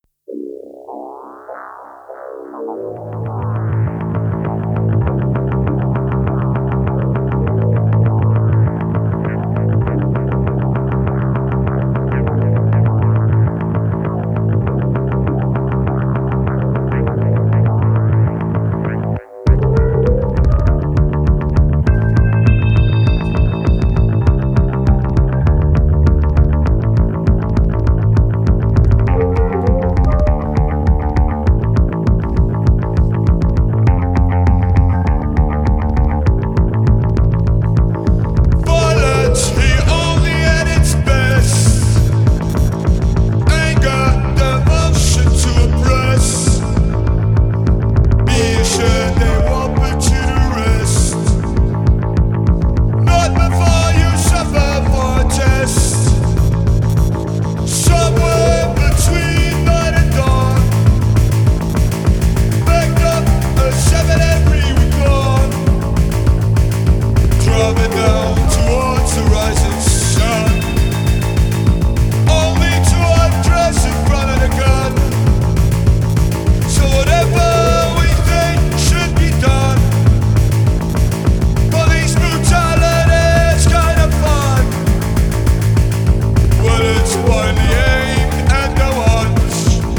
Electro Techno Wave